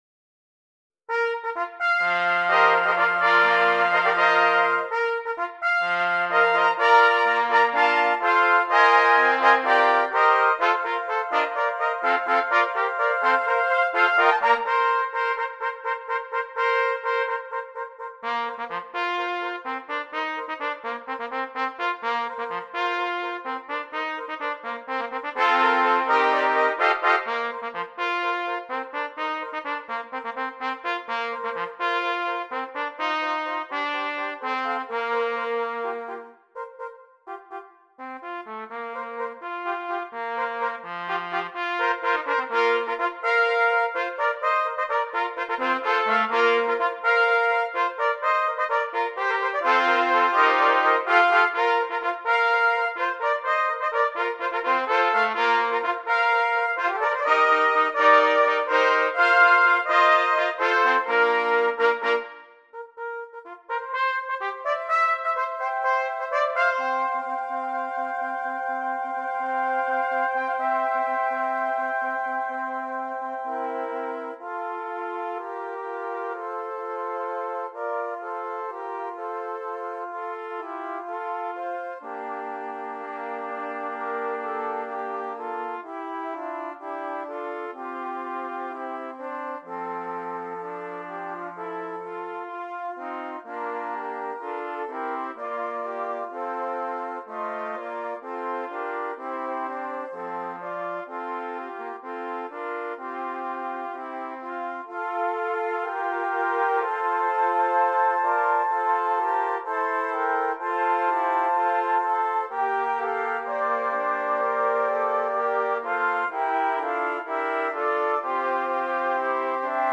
6 Trumpets